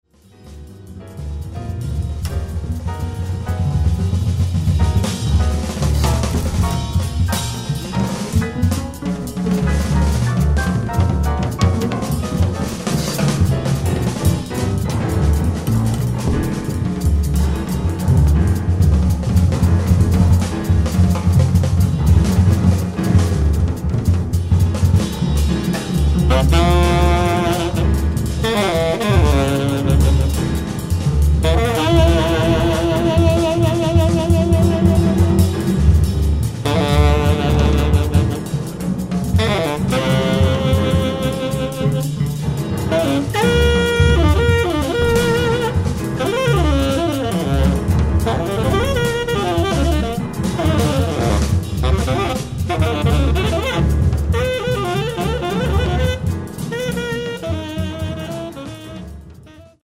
pianoforte
batteria, percussioni
sembra dare un attimo di tregua con l'espressivo pianoforte